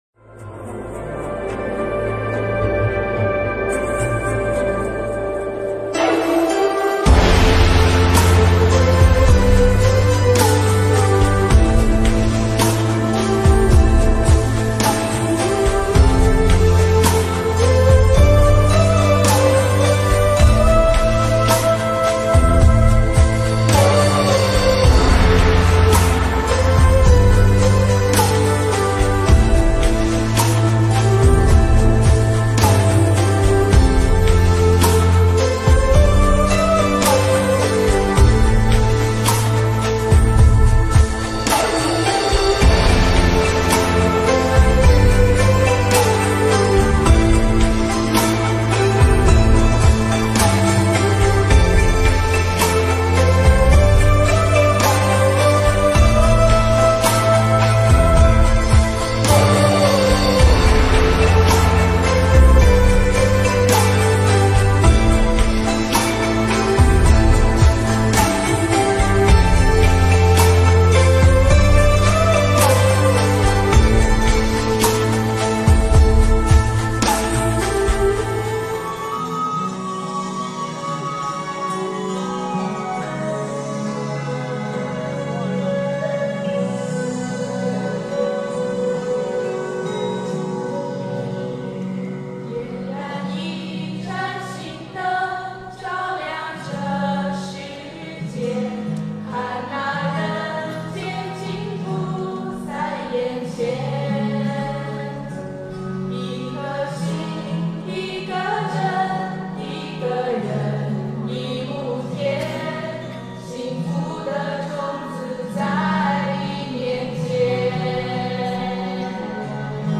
音频：新加坡佛友合唱《心灯》一曲一天堂、一素一菩提！